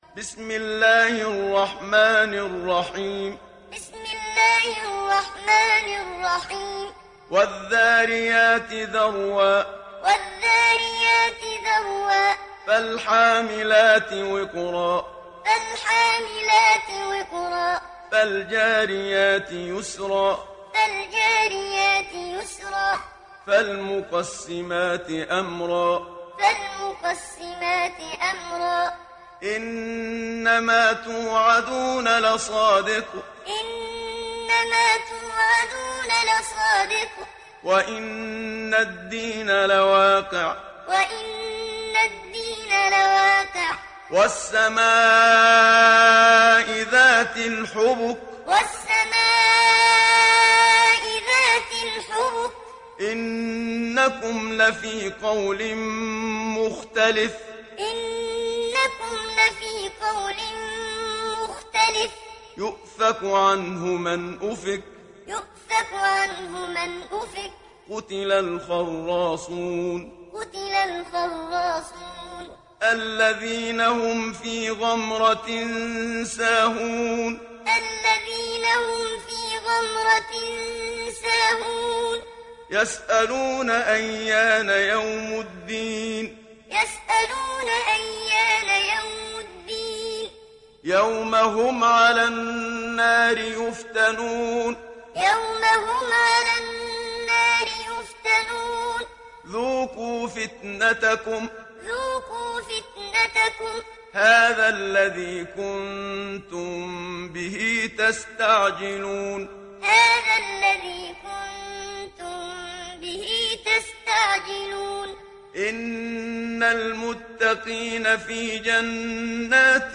Muallim